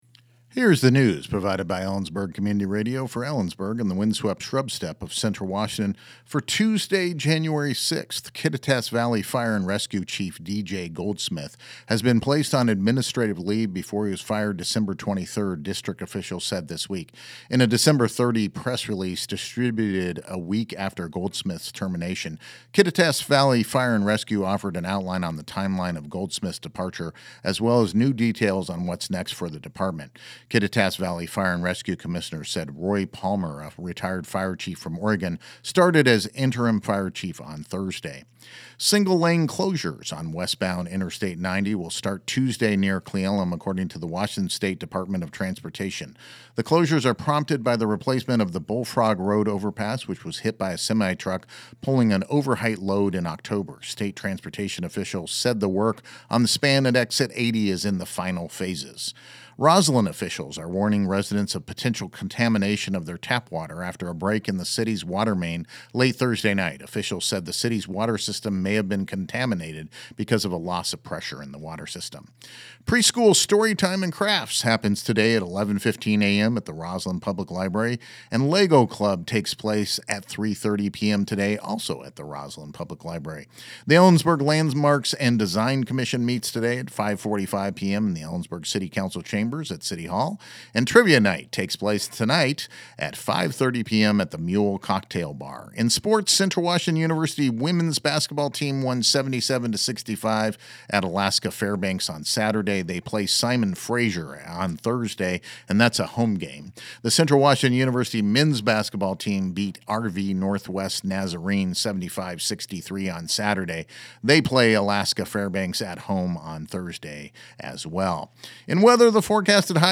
Here’s the news provided by Ellensburg Community Radio for Ellensburg and the wind-swept shrub steppe of Central Washington for Tuesday, January 6th